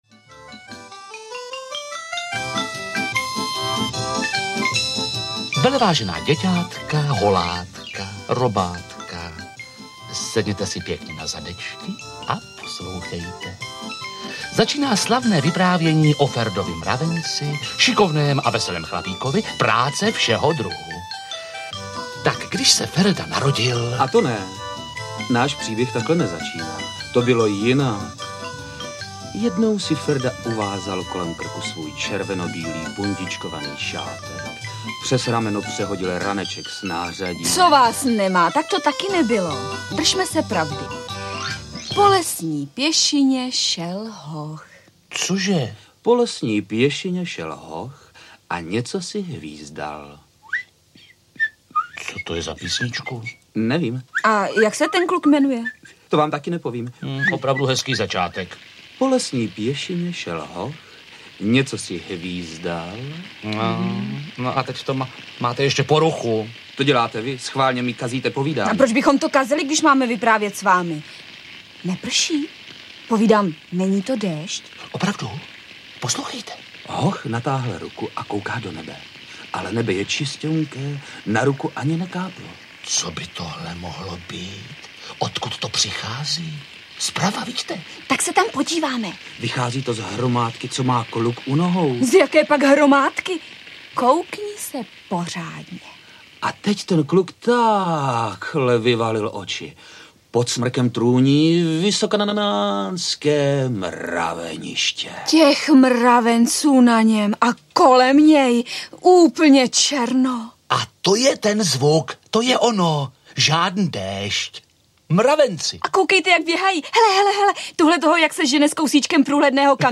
Ferda, Beruška a Pytlík & Ferda sporty všeho druhu audiokniha
Plejáda populárních českých herců účinkuje ve dvou příbězích podle knížek Ondřeje Sekory o Ferdovi Mravencovi a jeho přátelích
Ukázka z knihy
• InterpretKarel Augusta, Stanislav Fišer, Naďa Konvalinková, Jiří Lábus, Mirko Musil, Ilona Svobodová, Pavel Trávníček, Ladislav Trojan, Marcel Vašinka